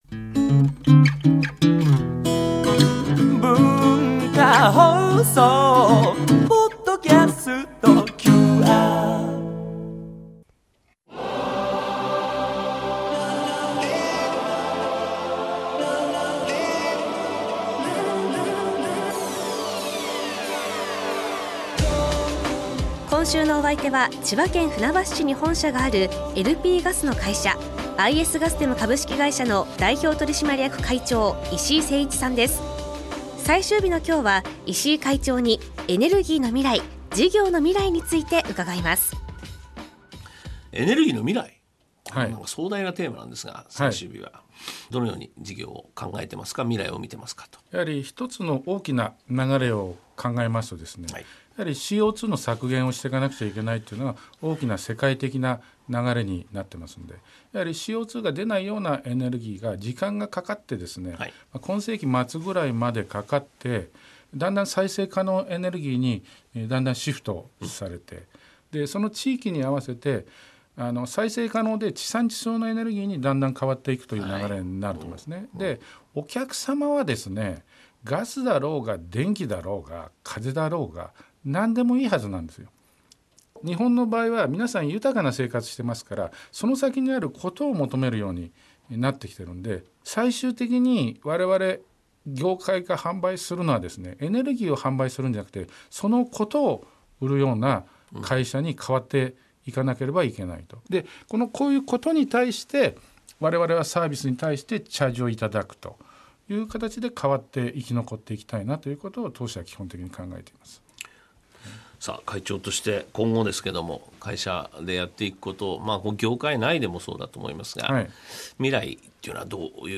毎週、現代の日本を牽引するビジネスリーダーの方々から次世代につながる様々なエピソードを伺っているマスターズインタビュー。
（月）～（金）AM7：00～9：00　文化放送にて生放送！